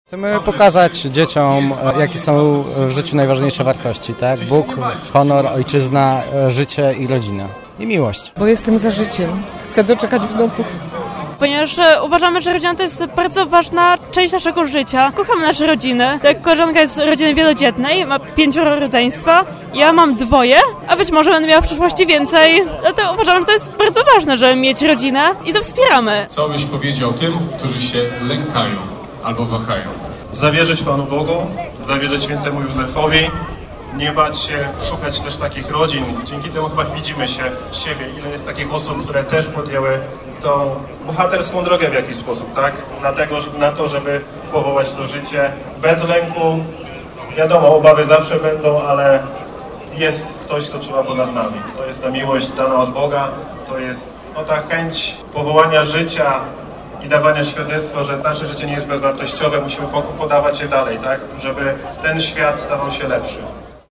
Marsz dla Życia i Rodziny ruszył dziś o 11:00 z Placu Zamkowego.